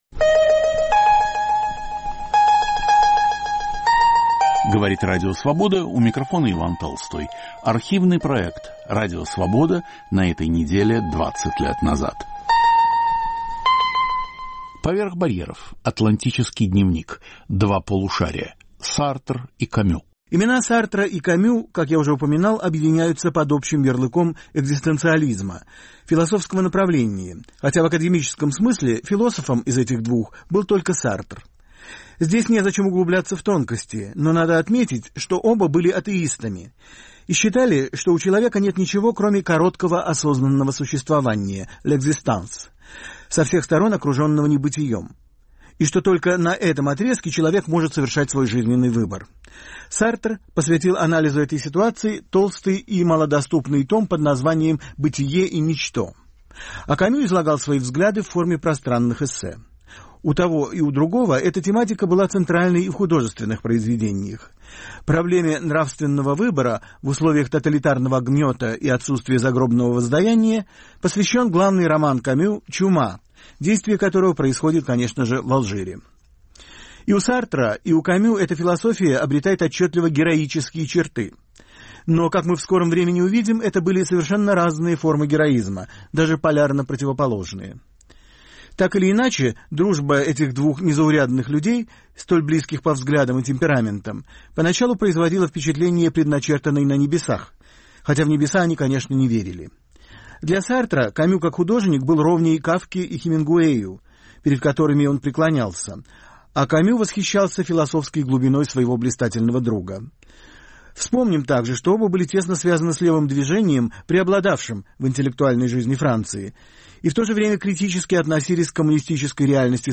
Автор и ведущий Алексей Цветков об экзистенциалистах. Иван Толстой беседует в прямом эфире с лауреатом Юрием Арабовым, гостями и слушателями в Московской студии Радио Свобода.